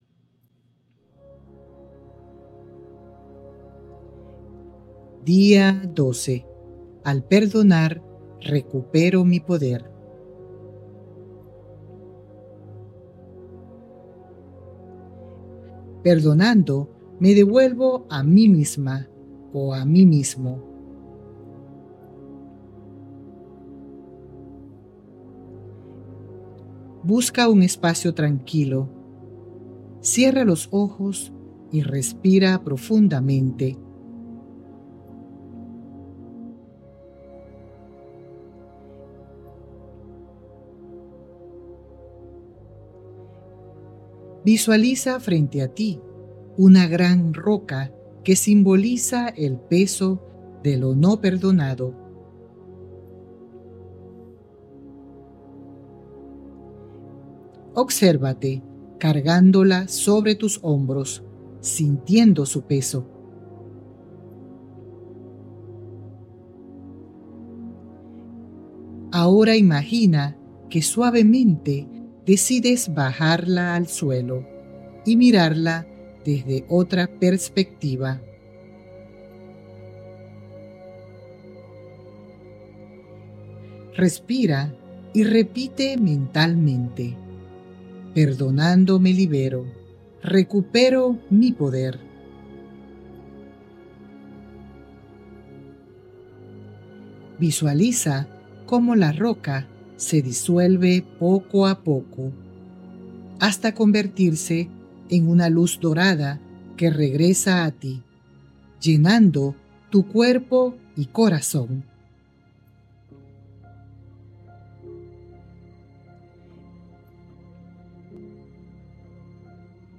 🎧 Meditación Guiada: «Perdonando me devuelvo a mí mismo»